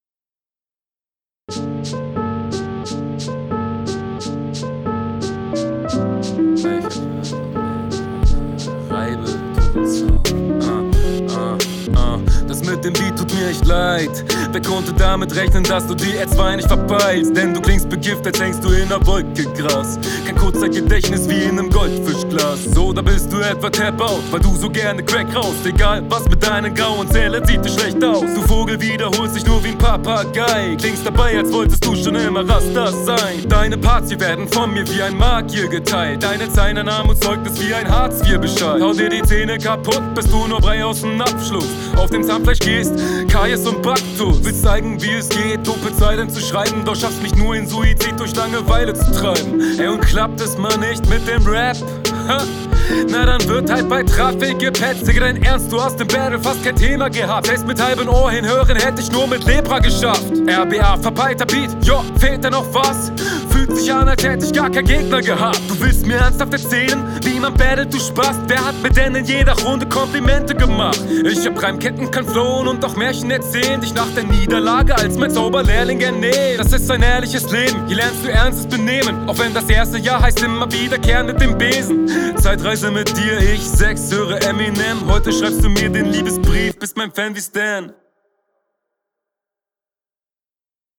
Der Stimmeinsatz war mir ein bisschen zu aggressiv an manchen Stellen.
Soundquali ist besser als bei deinem Gegner, lässt sich gut hören.